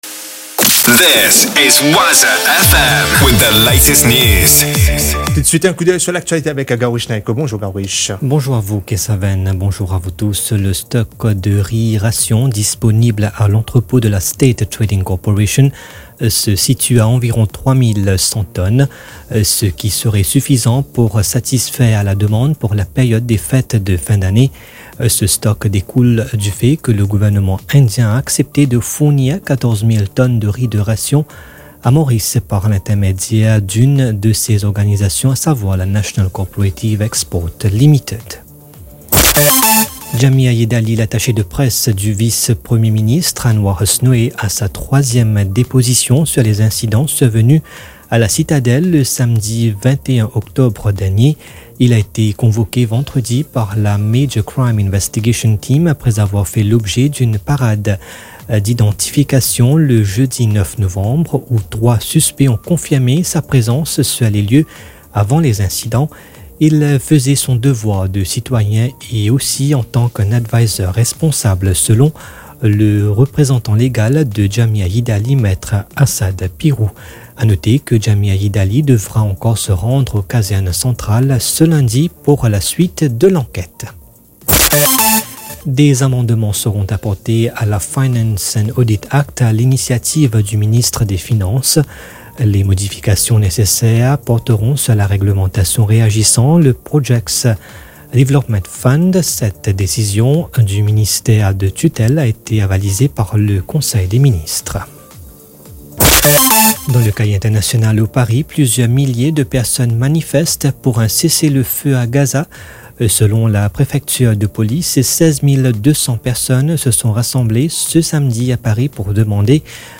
news 10h - 12.11.23